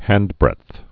(hăndbrĕdth) also hand's-breadth or hand's breadth (hăndz-)